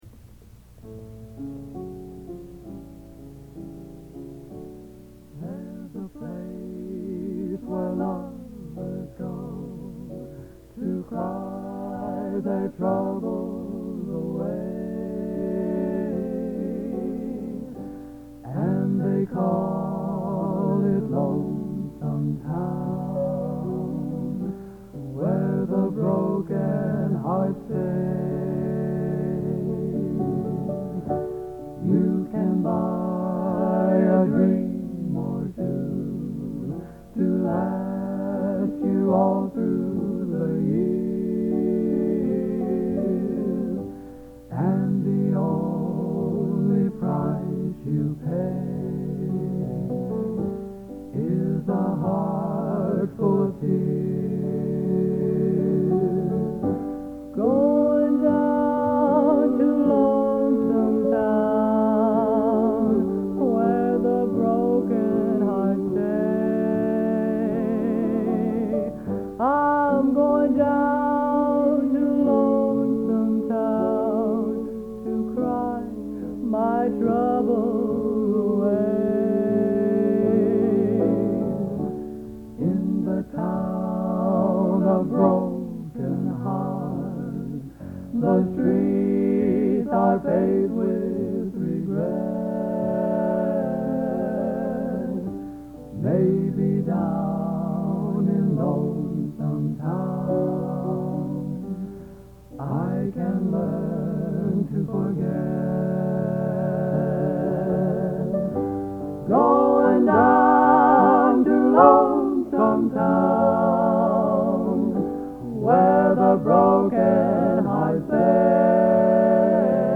Genre: Country/Western | Type: End of Season